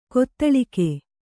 ♪ kottaḷike